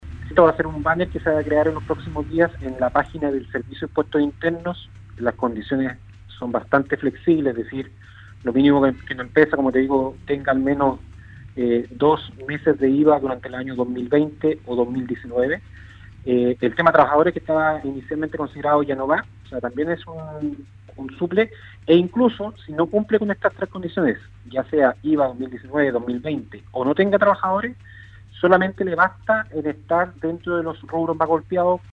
En conversación con Radio Sago el Seremi de Economía en la Región de Los Lagos, Francisco Muñoz, se refirió a las dos leyes recientemente aprobadas por el Senado que entregarán bonos y beneficios tributarios a más de 820 mil micro, pequeñas y medianas empresas a nivel nacional, en el marco del acuerdo de los mínimos comunes entre el gobierno y la oposición.